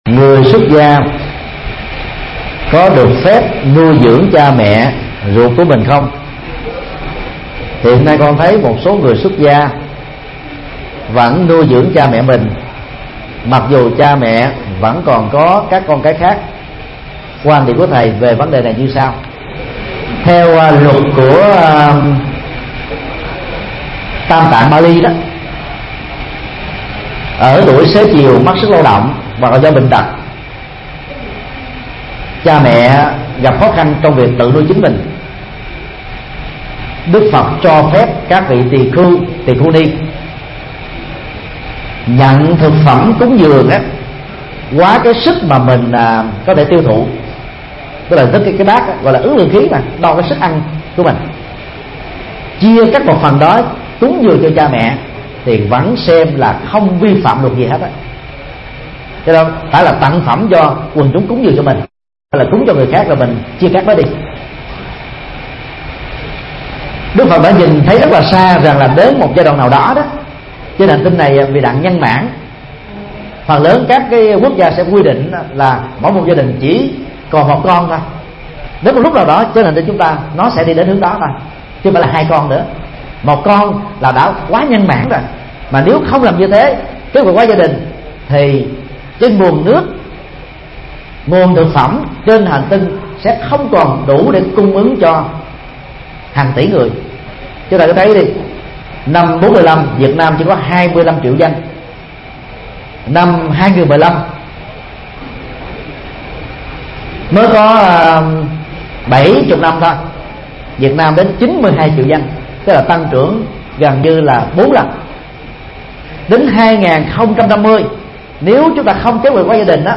Vấn đáp: Người xuất gia báo hiếu